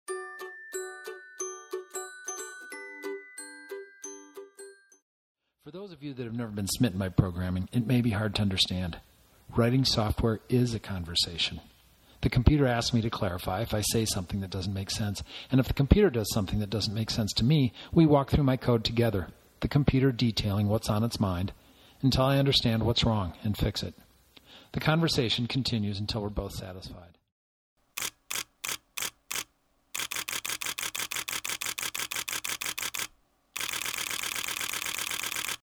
My remix has three sources, two of which are not mine.
mixdown.mp3